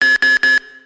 buzzer2.wav